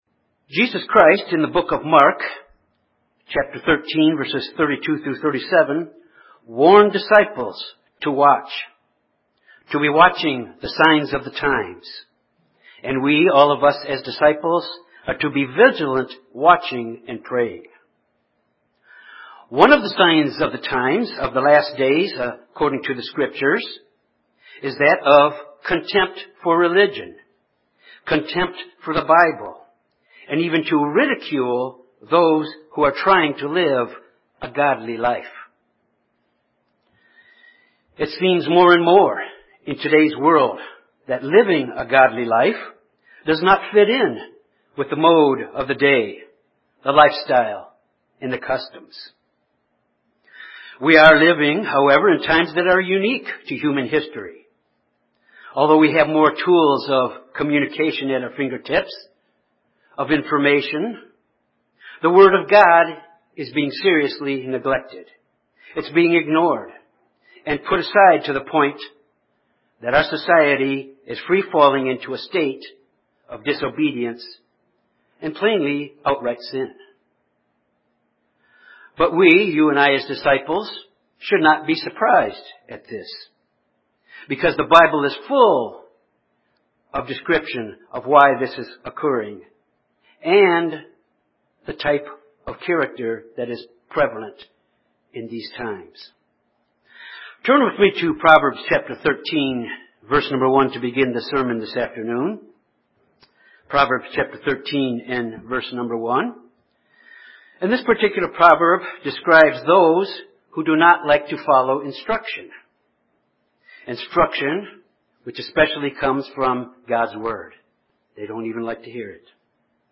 This sermon examines the dangerous characteristic traits of the mockers or the scorners. It’s a characteristic of the day and times that we live in now, especially as we see more and more the rejection of God, the rejection of His word and His way of life as one of the signs of the times.